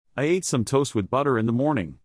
The sentence should have sounded like this (the bold words are the stressed ones):
Notice how you slow down every time you get to an important word, and quickly pass over the less important ones?